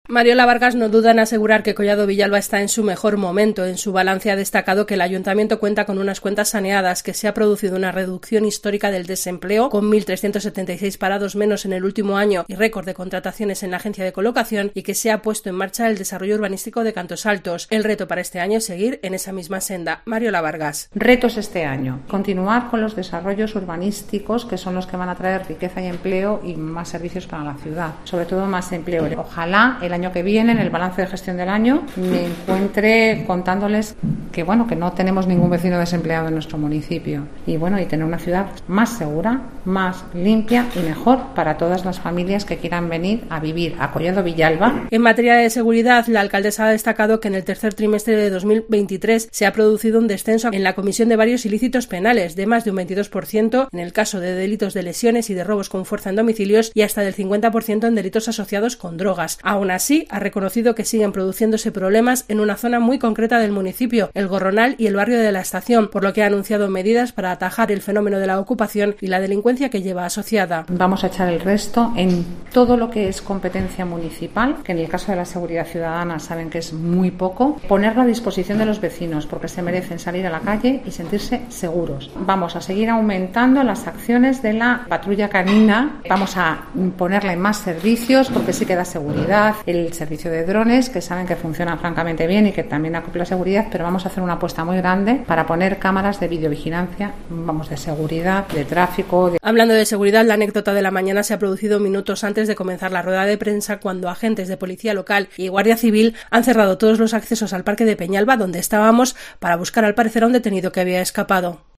La alcaldesa de Collado Villalba, Mariola Vargas, ha presentado este miércoles, acompañada por el resto de concejales del equipo de Gobierno, el balance de los primeros seis meses de esta legislatura, en la que el PP vuelve a gobernar en solitario.